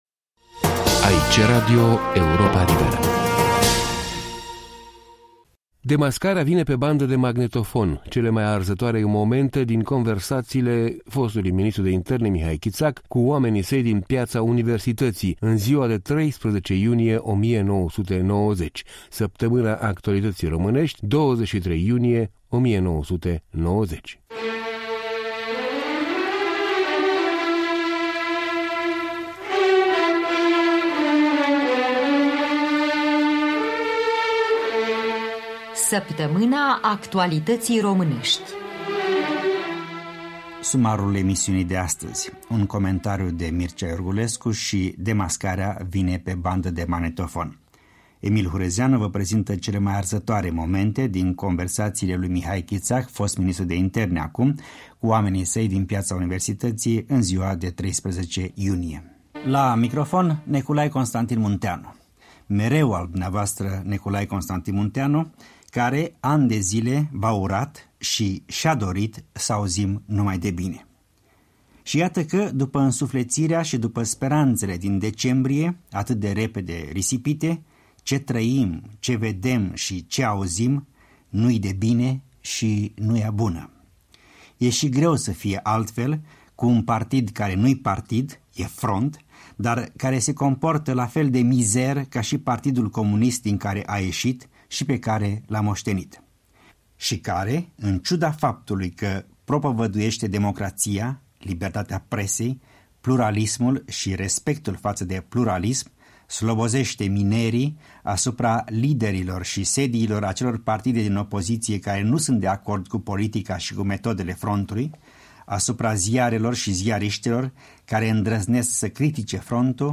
23.06.1990 - Variațiuni și documente sonore în jurul mineriadei de la 13-15 iunie